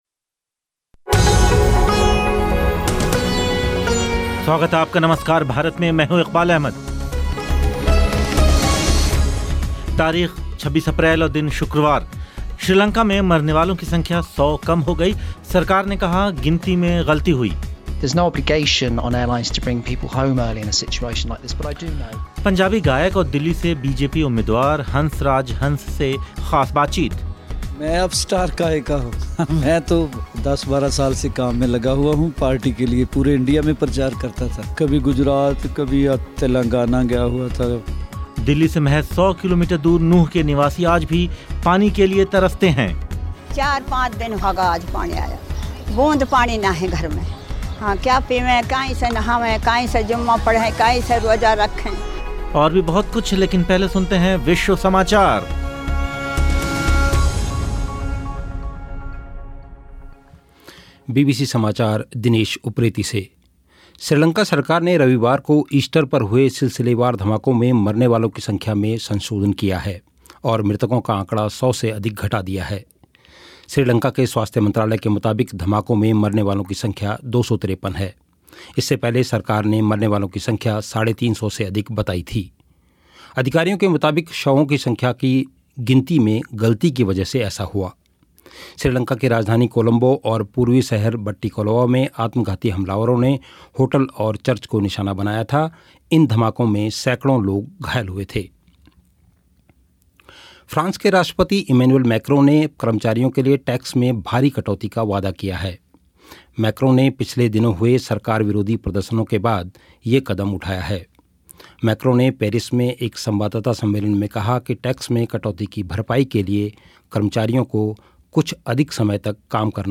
पंजाबी गायक और दिल्ली से बीजेपी उम्मीदवार हंस राज हंस से ख़ास बातचीत